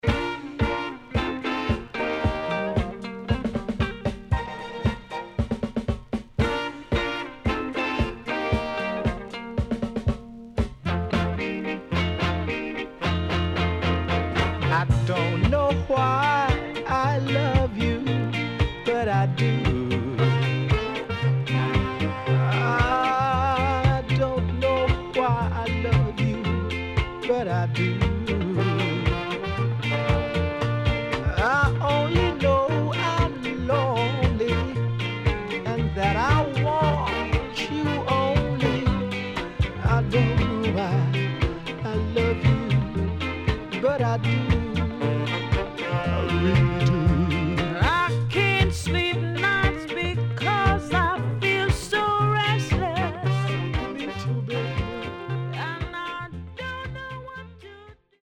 HOME > REGGAE / ROOTS  >  RECOMMEND 70's
SIDE B:うすいこまかい傷ありますがノイズあまり目立ちません。